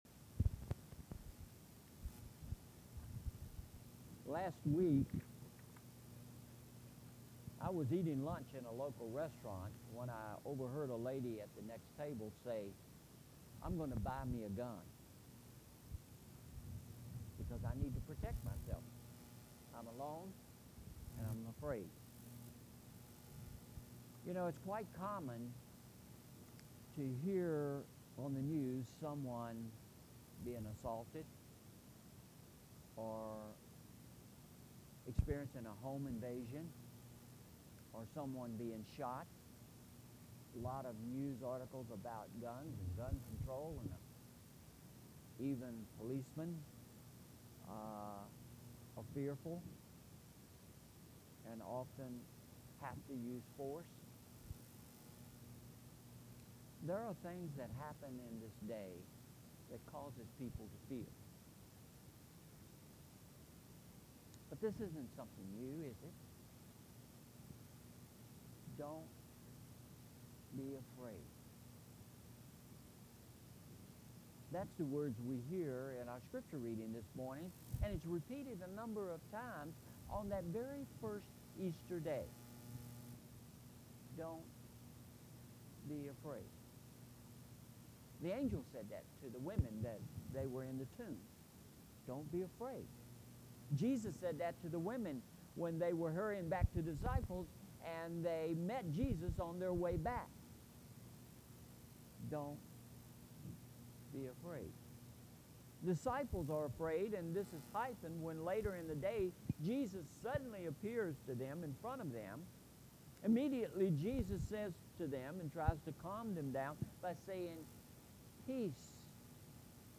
Sermon Title: “Don’t Be Afraid”